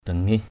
/d̪a-ŋih/ (đg.) không bằng lòng = ne pas supporter. displease. dangih akaok dz{H a_k<K lắc đầu = refuser d’un signe de tête. dangih makaik dz{H m=kK căm tức = irrité....